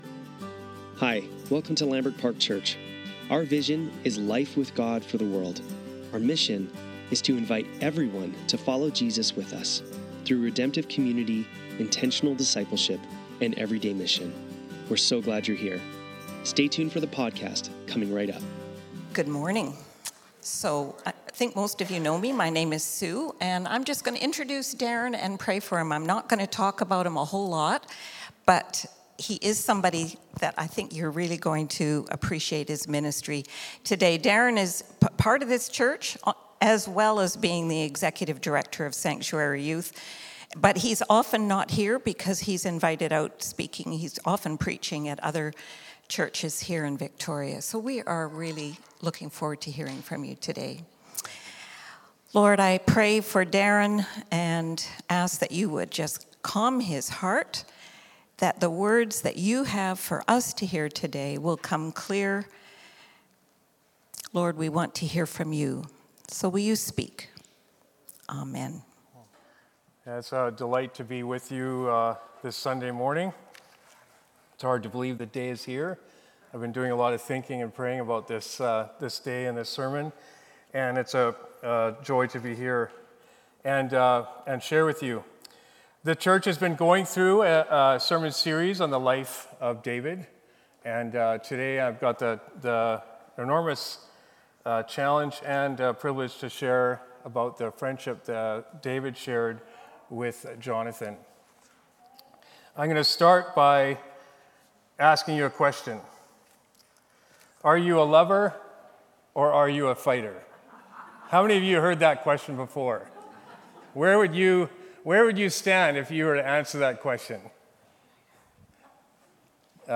Sermons | Lambrick Park Church
Sunday Service - July 27, 2025